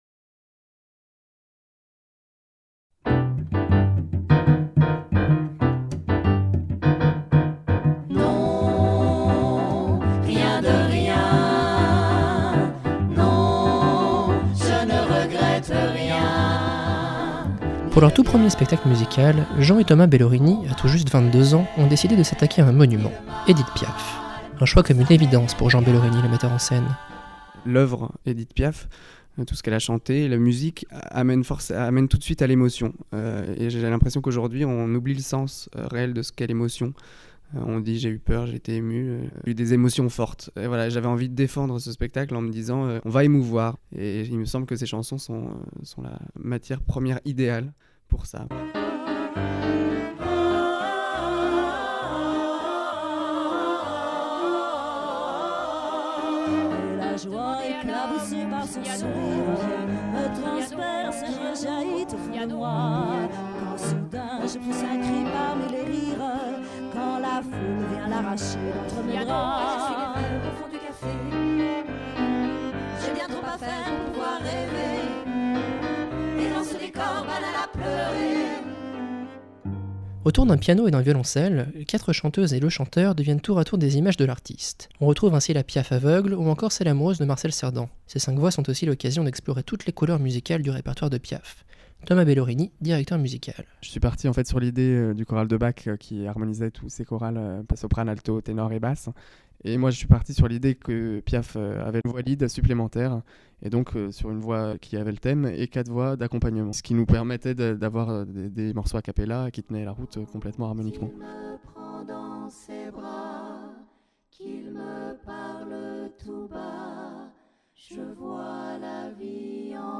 Interview radio de l'équipe sur fond musical